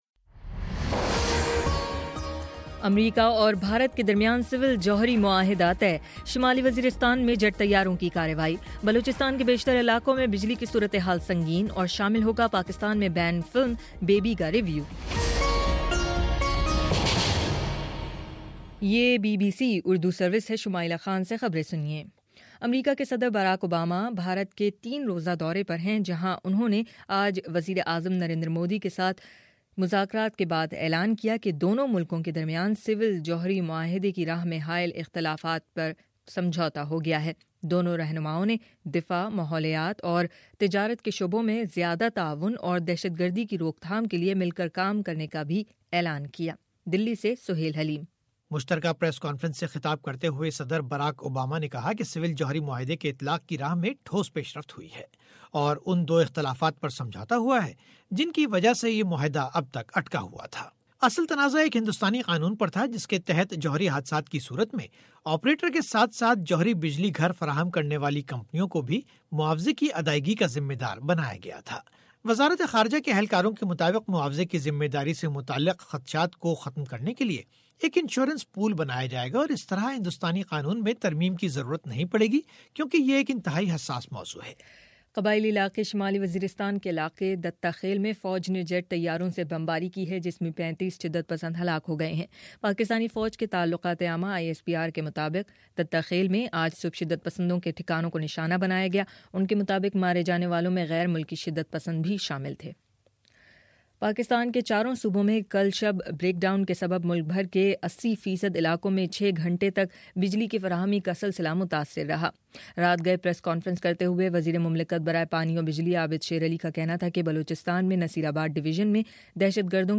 جنوری 25: شام سات بجے کا نیوز بُلیٹن